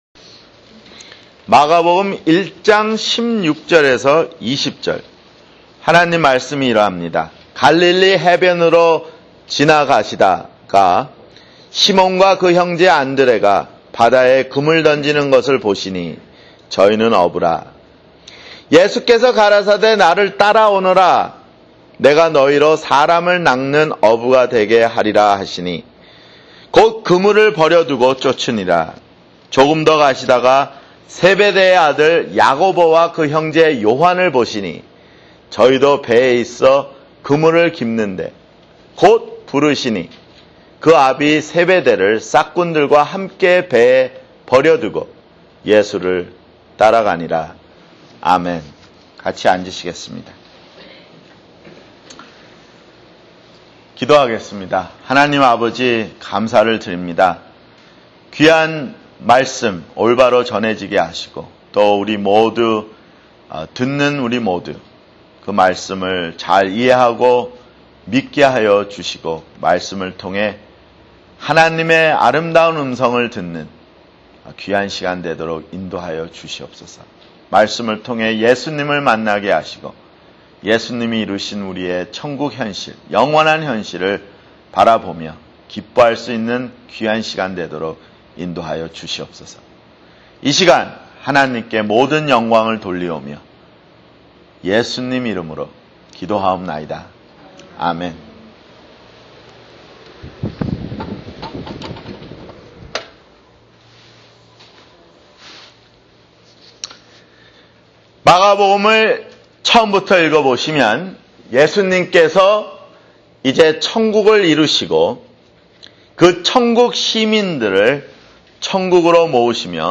[주일설교] 마가복음 1:16-20